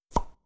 toy-take.wav